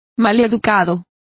Complete with pronunciation of the translation of churlish.